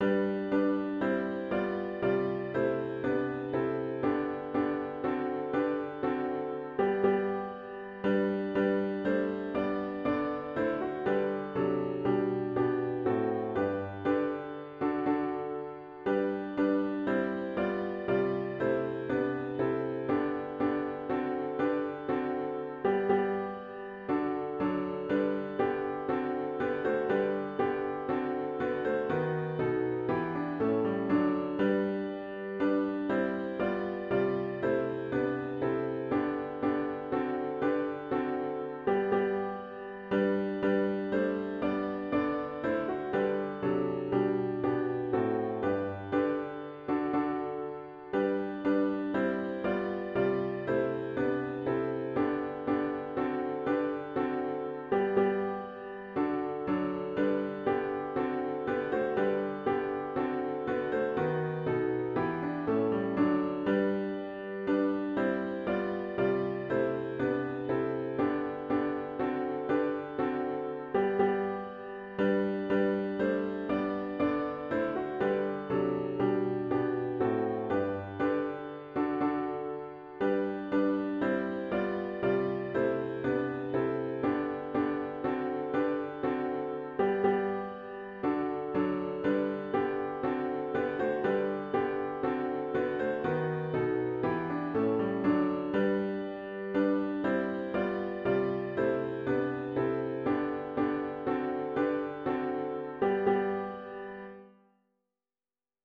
OPENING HYMN “Christ Is Risen! Shout Hosanna!”